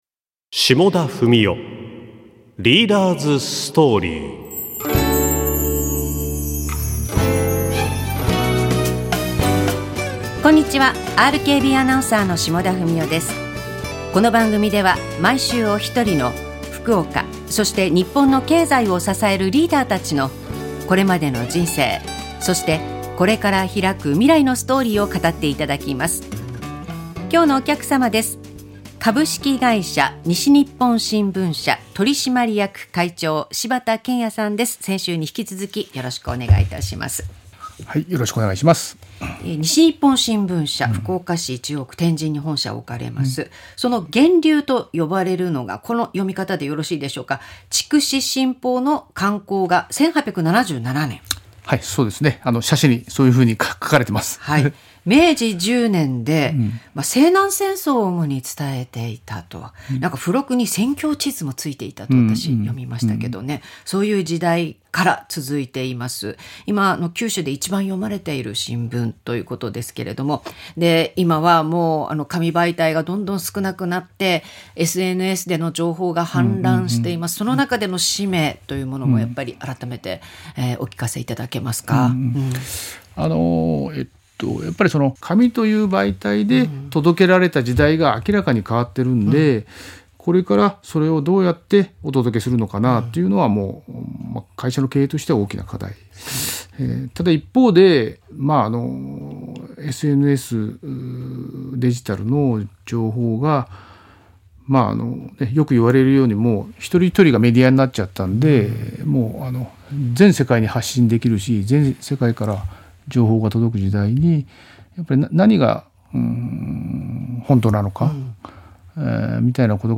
ラジオ
2025年1月13日　番組でおかけした曲は著作権の制限によりカットしています。ご了承ください